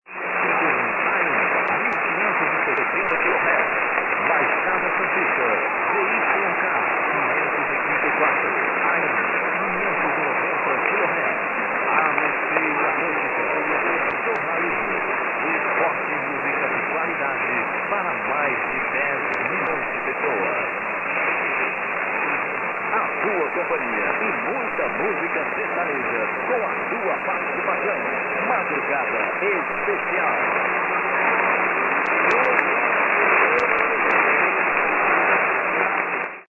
Note also that there are short blackouts and breaks in just about every recording because of computer not being fast enough.
Finally, an example of the possibilities offered by IF recording. Setting IF frequency at 1565 kHz, I was able to record both 1560 and 1570 kHz, and possible split frequencies as well.